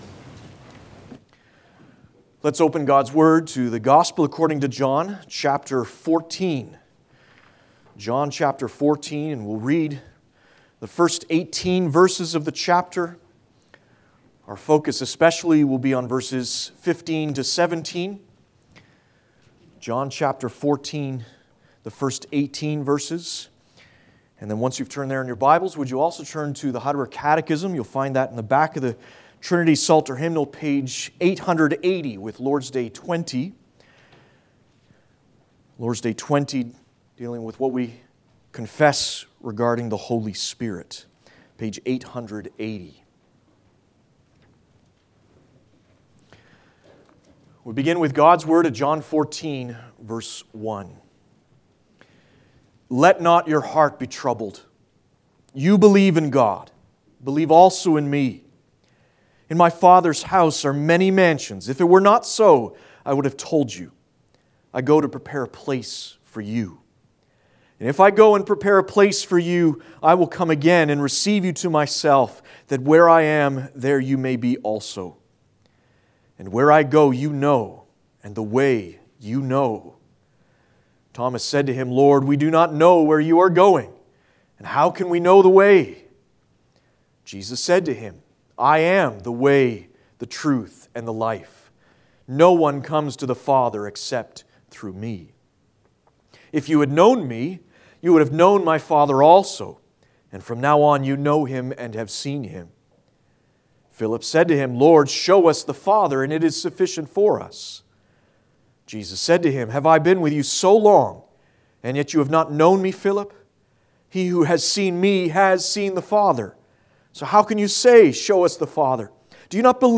Passage: John 14:1-18 Service Type: Sunday Afternoon